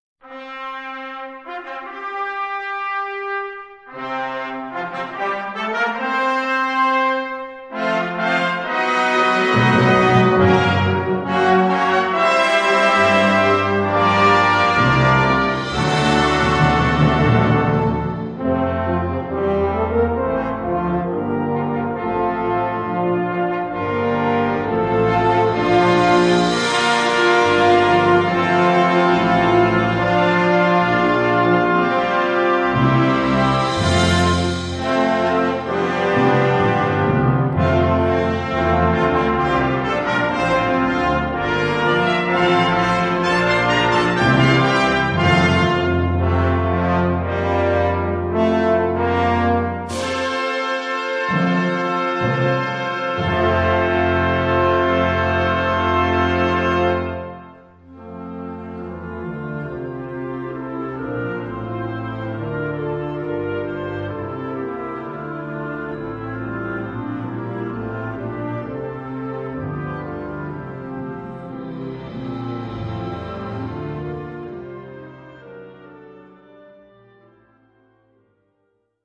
Gattung: Int. Hymne der Menschenrechte
Besetzung: Blasorchester